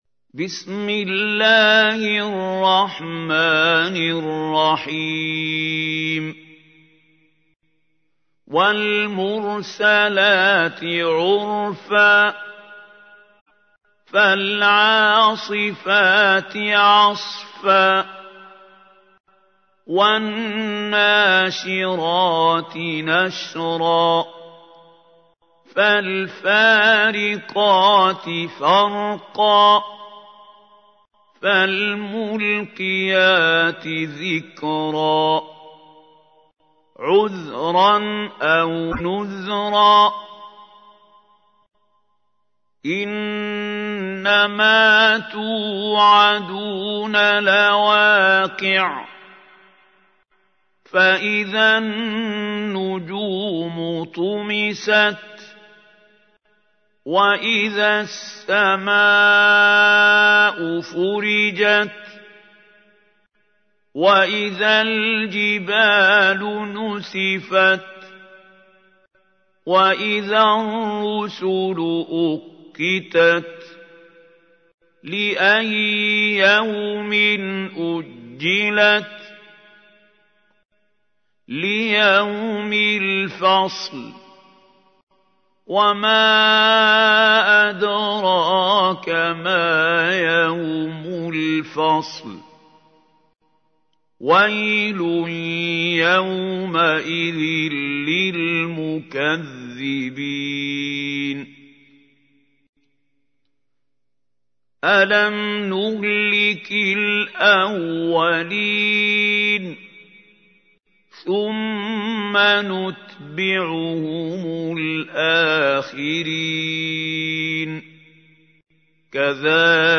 تحميل : 77. سورة المرسلات / القارئ محمود خليل الحصري / القرآن الكريم / موقع يا حسين